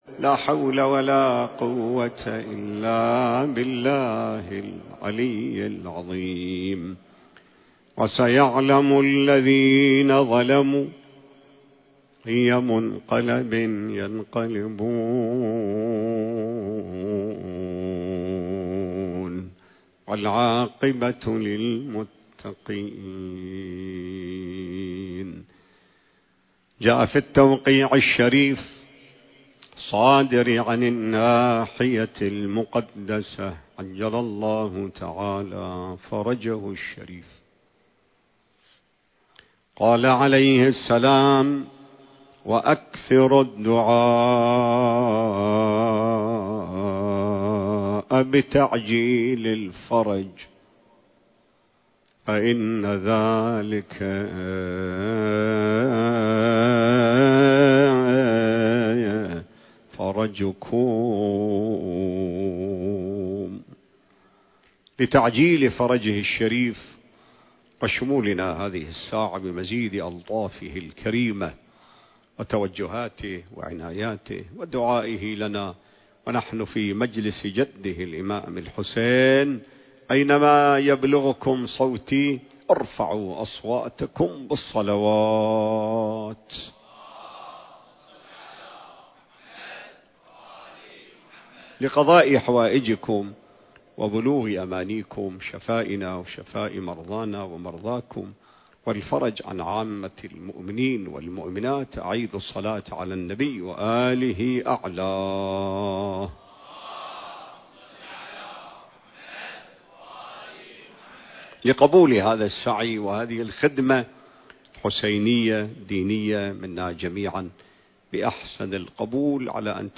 المكان: هيئة نهج علي (عليه السلام) / البصرة التاريخ: 2025